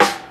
• Short Snare Drum Sound G Key 171.wav
Royality free snare tuned to the G note. Loudest frequency: 1625Hz
short-snare-drum-sound-g-key-171-ix0.wav